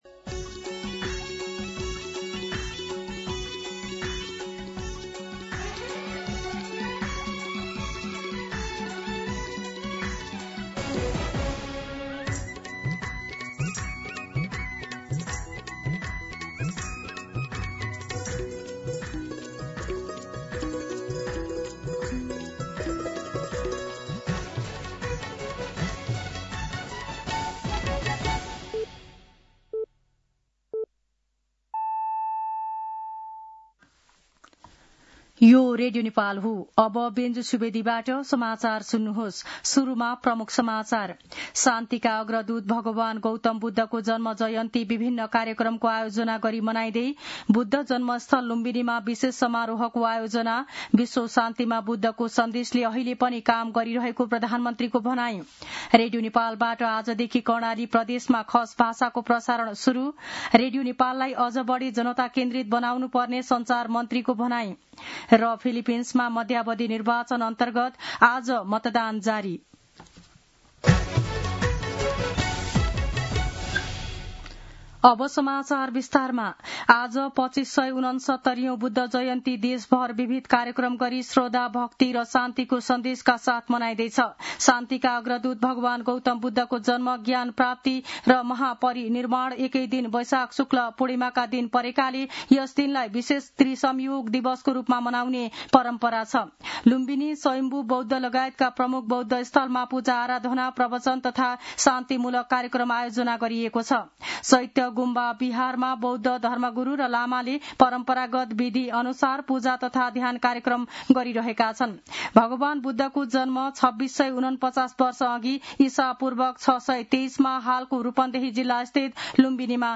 दिउँसो ३ बजेको नेपाली समाचार : २९ वैशाख , २०८२
3pm-Nepali-News-01-29.mp3